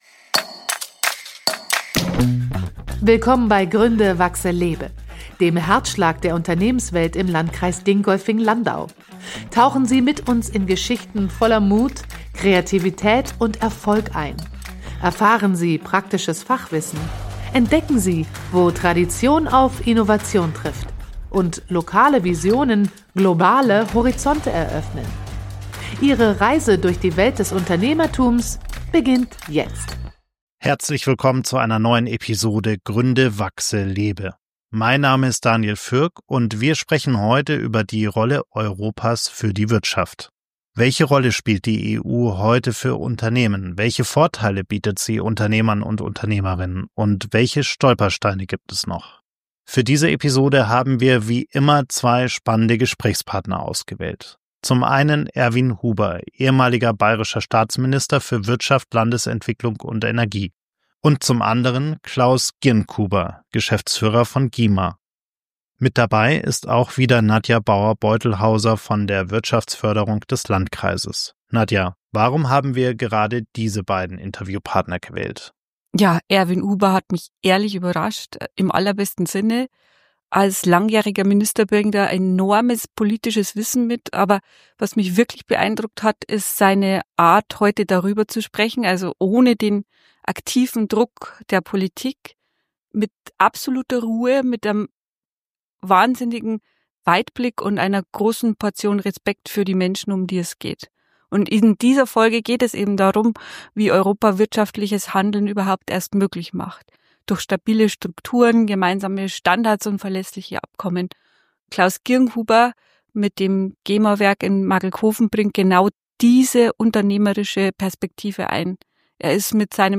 Es geht um Chancen des Binnenmarkts, um geopolitische Unsicherheiten, Innovationsförderung und die gemeinsame Verantwortung für demokratische Werte. Ein Gespräch über Märkte, Haltung und das, was uns als Europa verbindet – nahbar, meinungsstark und zukunftsgerichtet.